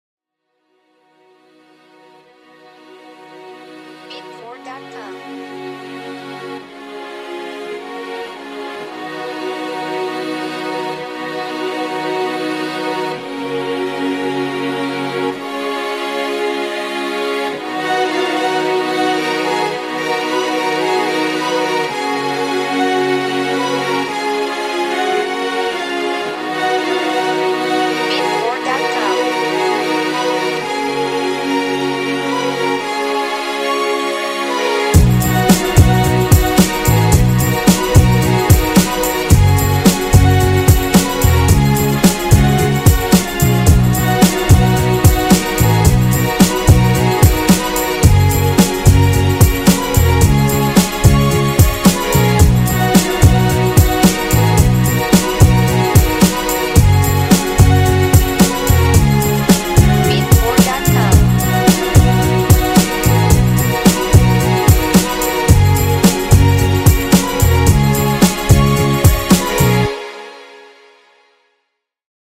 Instruments: Strings Bass Guitar